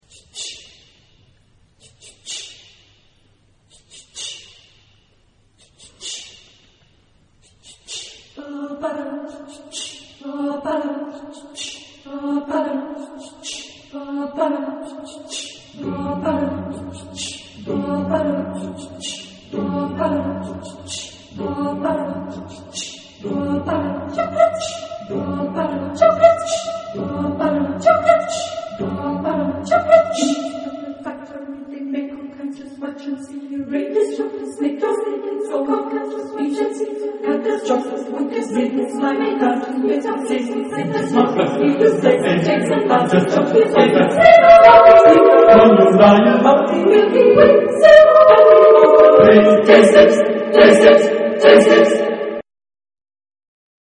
Texte en : anglais ; onomatopées
Caractère de la pièce : joyeux ; humoristique
Type de choeur : SATB  (4 voix mixtes )
Tonalité : atonal